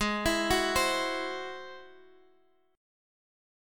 Ab+7 chord